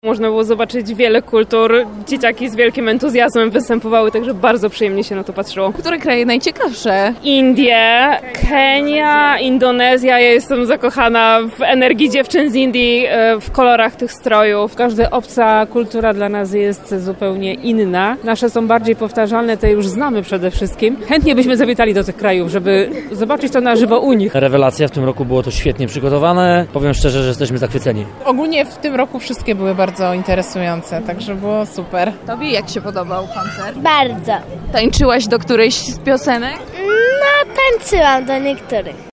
31dzieci_gor_sonda.mp3